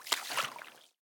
swim3.ogg